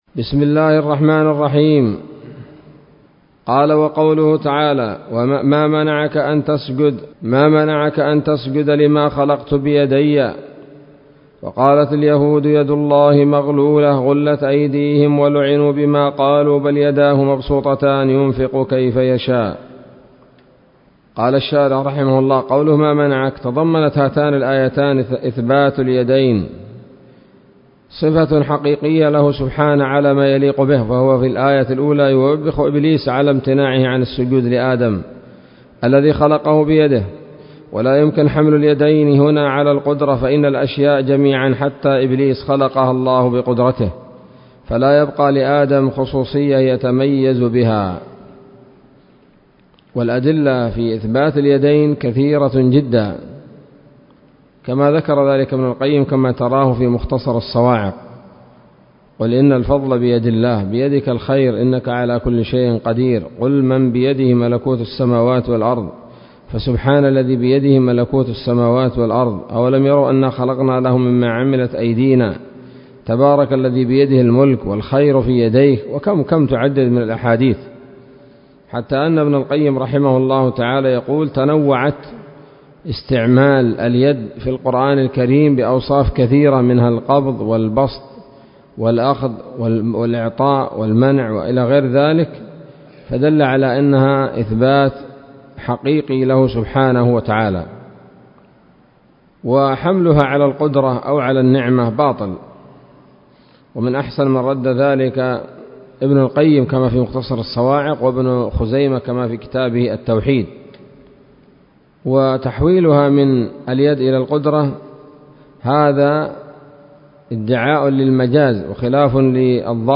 الدرس الواحد والستون من شرح العقيدة الواسطية للهراس